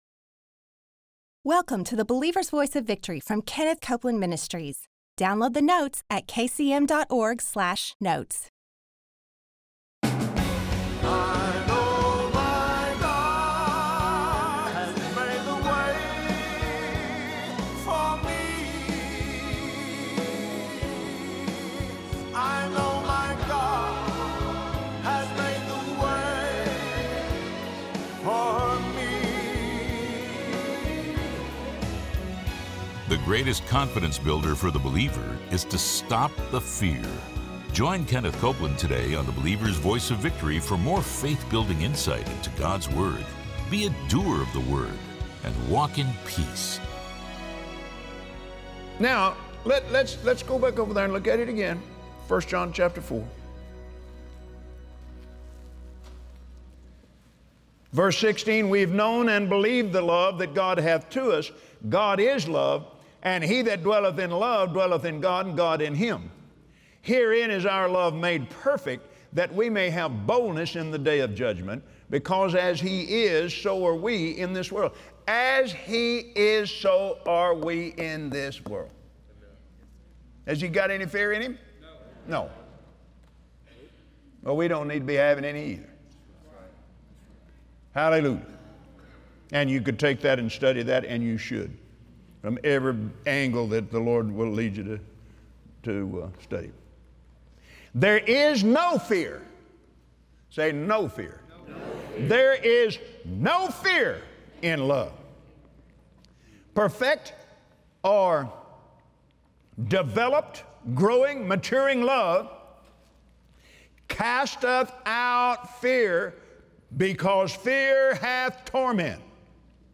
Believers Voice of Victory Audio Broadcast for Wednesday 06/07/2017 Stop the fear brought on by the lies of the enemy! Kenneth Copeland shares on the Believer’s Voice of Victory how Satan undermines your trust in the love God has for you and incites fear by stealing God’s Word from your mouth.